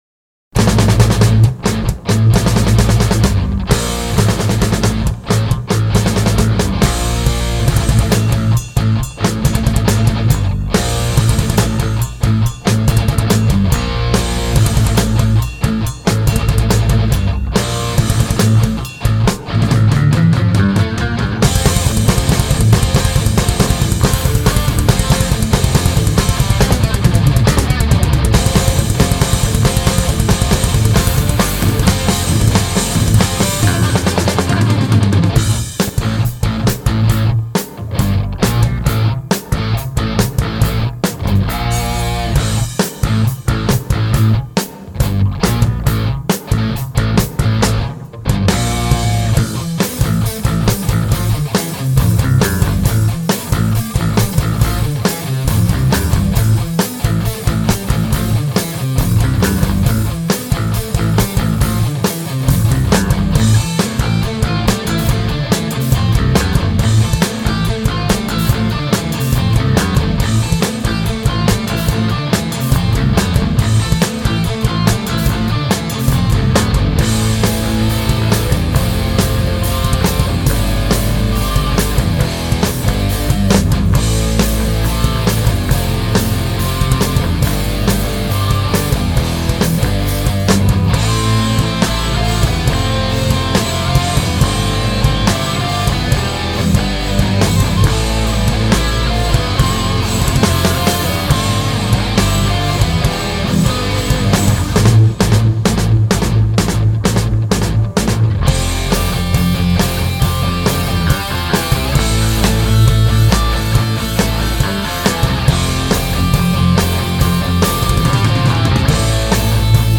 Guitarra/voz
Guitarra Solista
Batería